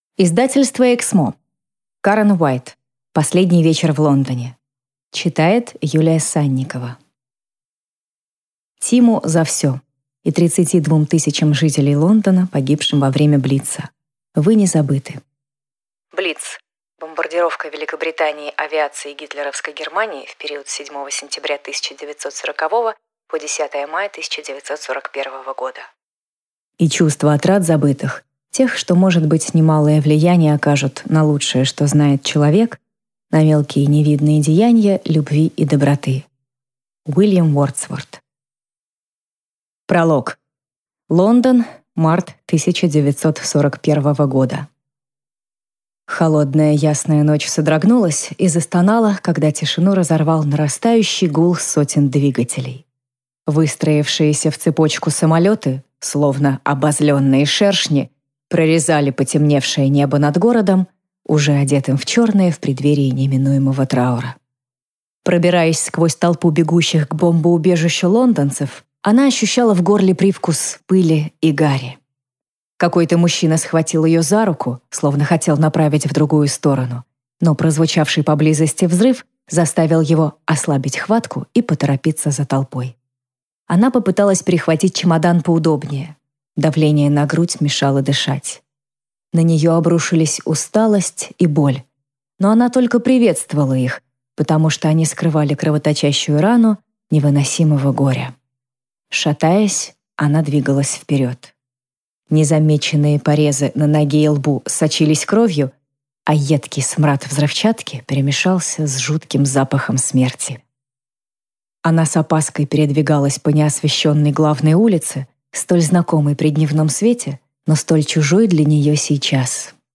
Аудиокнига Последний вечер в Лондоне | Библиотека аудиокниг
Прослушать и бесплатно скачать фрагмент аудиокниги